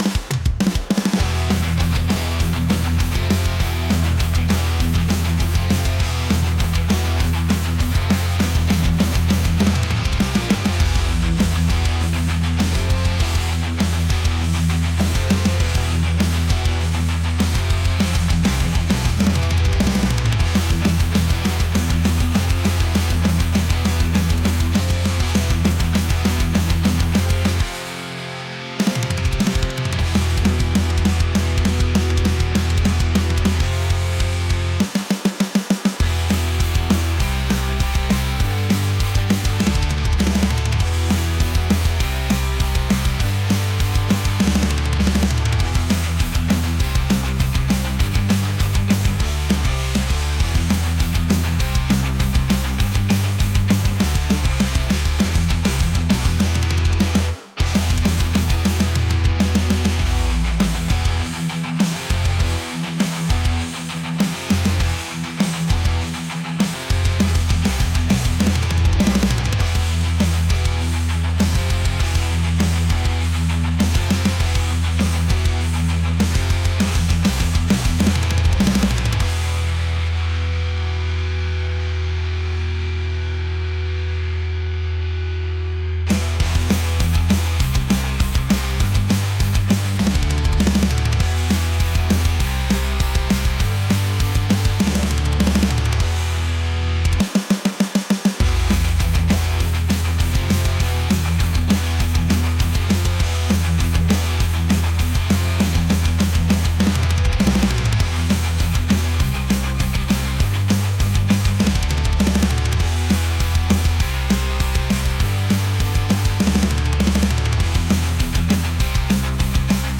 aggressive | punk | intense